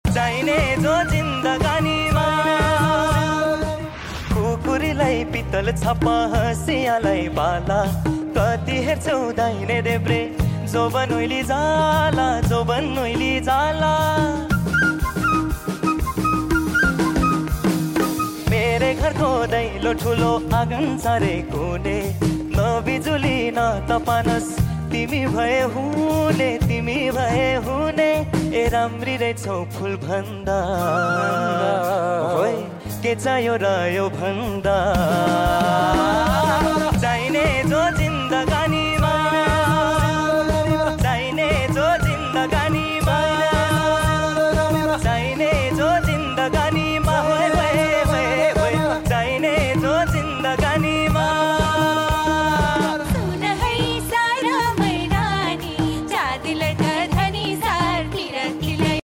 पूर्वी तराईको मोरङ जिल्ला मा अवस्थित सुन्दर हाट बजारहरू घुम्ने क्रममा कैद गरिएको सानो भिडियो।